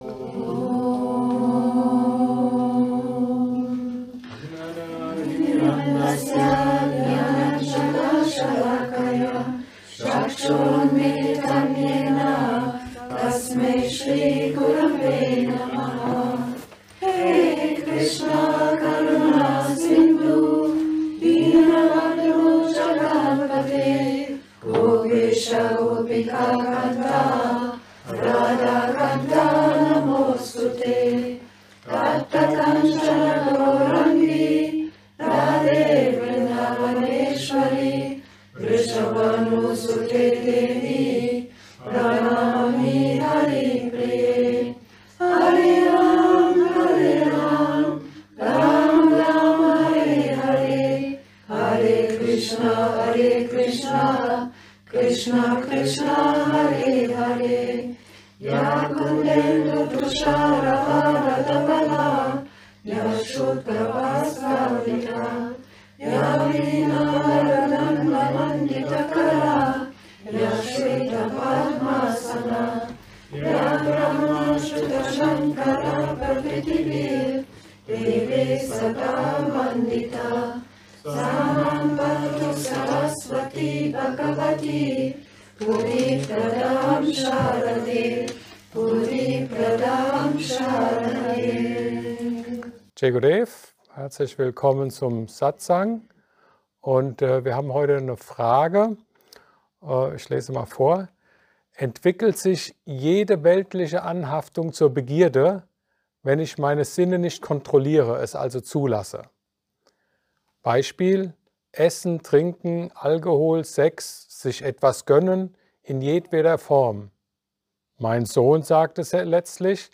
Satsang